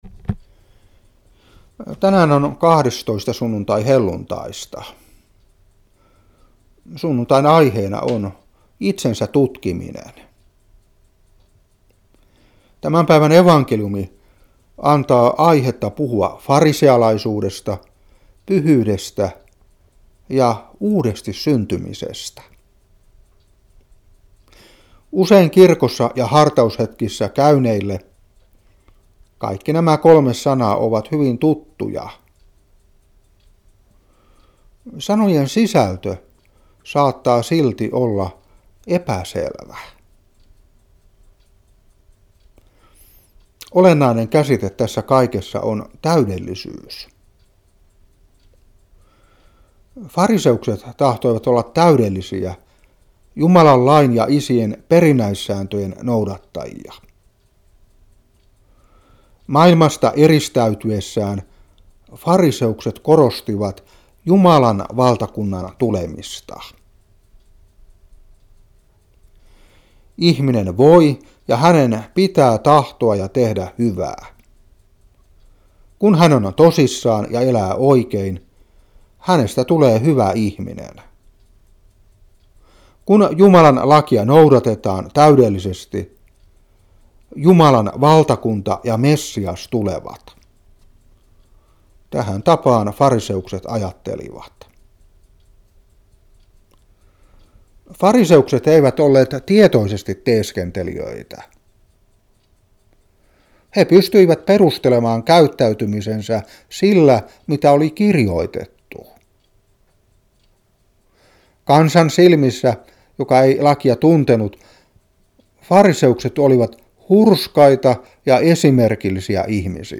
Saarna 2015-8. Matt.23:1-12.